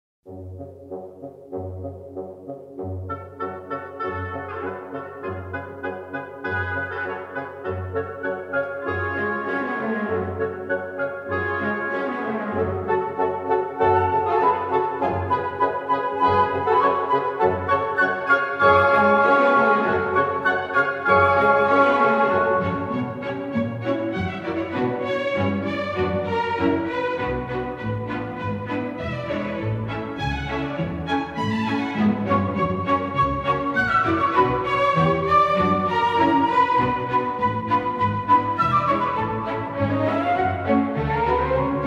Категория: Классические рингтоны